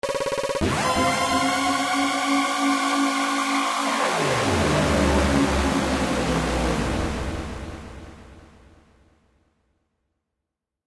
deltarune fountain open Meme Sound Effect
deltarune fountain open.mp3